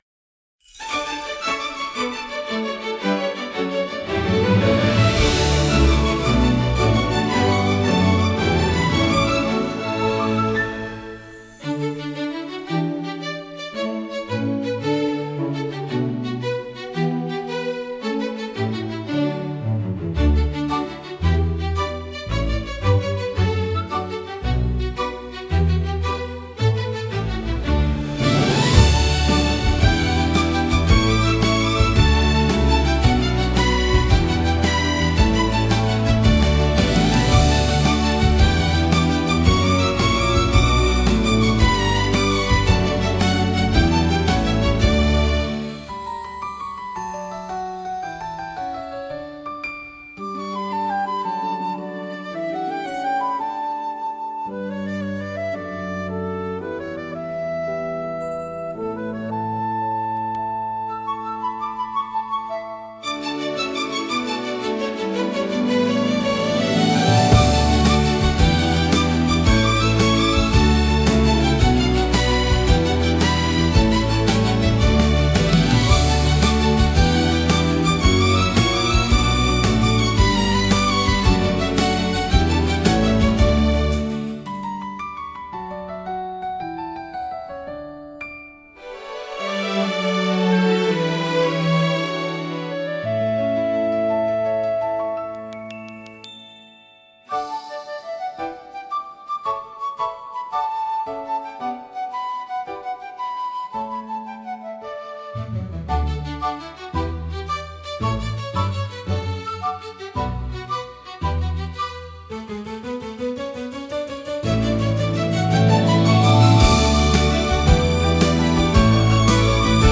感情に寄り添い、穏やかな幸せの空気を演出することで、心を和ませる効果があります。